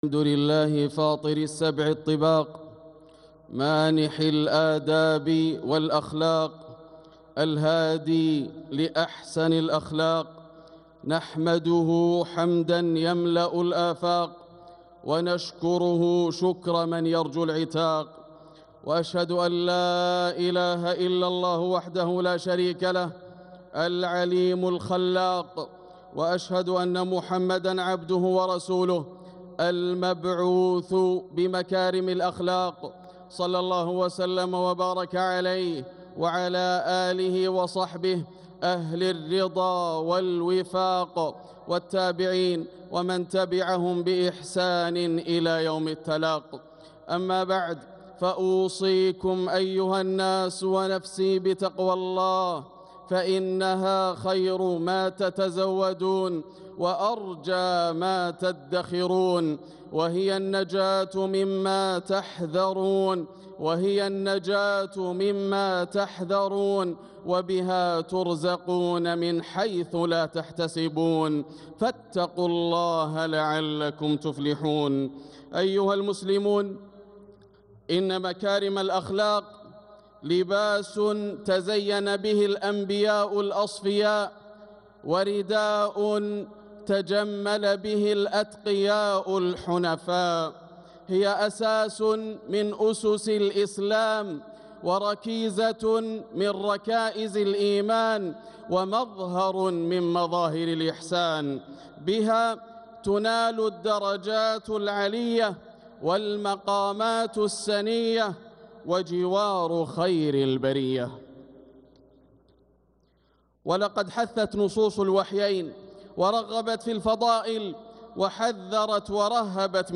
خطبة الجمعة 5-6-1446هـ بعنوان مكارم الأخلاق > خطب الشيخ ياسر الدوسري من الحرم المكي > المزيد - تلاوات ياسر الدوسري